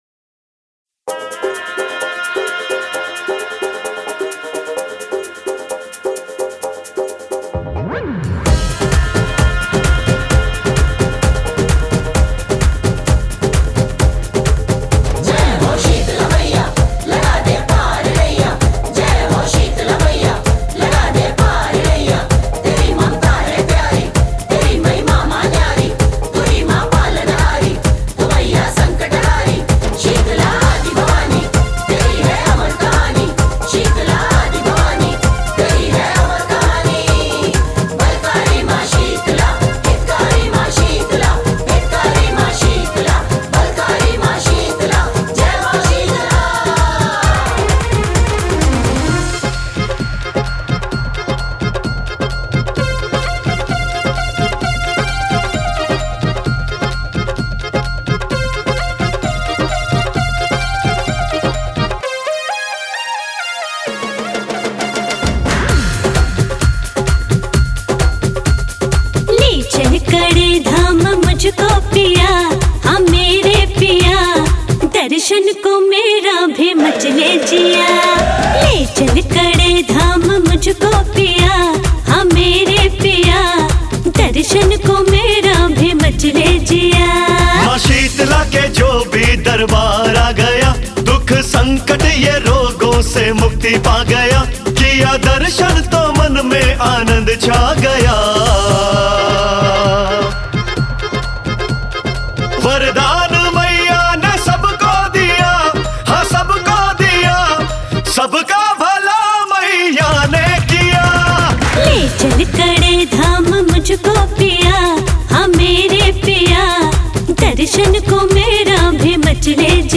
bhajan album